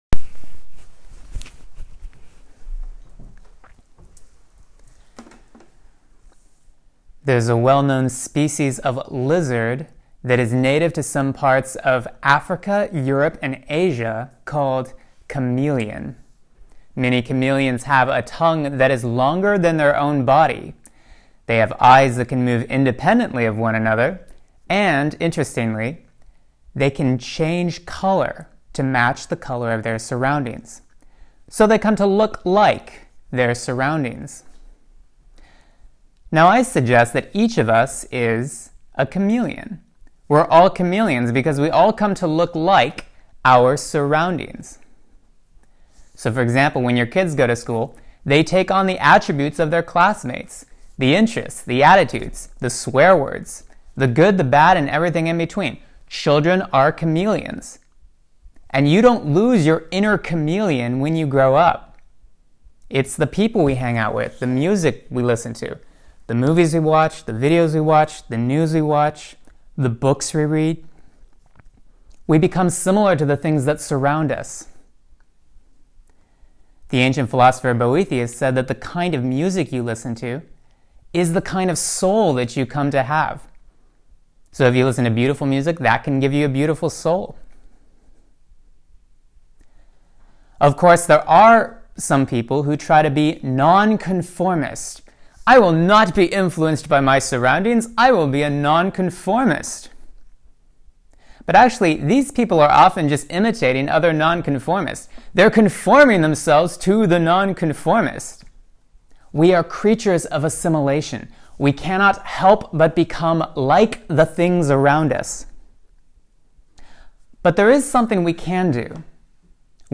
This homily was delivered to the student brothers at compline.